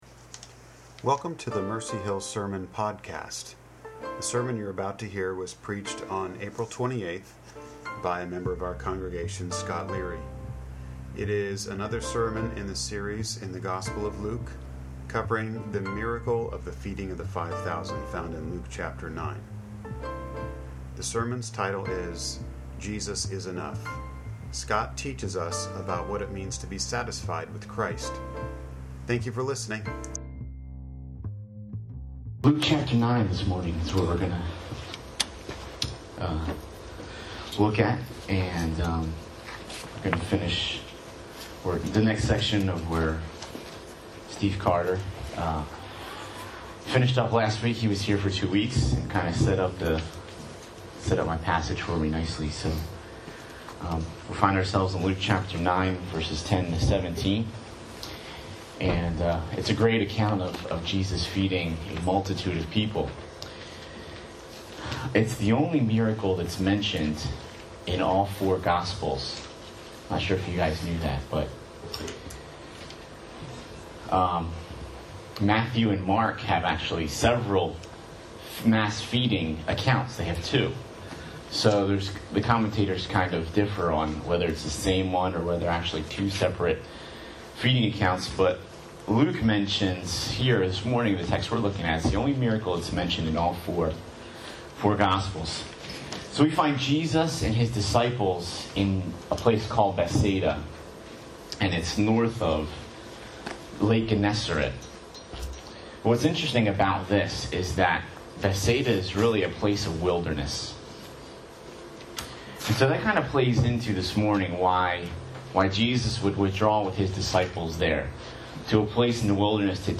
Mercy Hill Presbyterian Sermons - Mercy Hill NJ
It was given on the occasion of our Church's 4th Anniversary as we gathered at Atkinson Park on 10/5/14.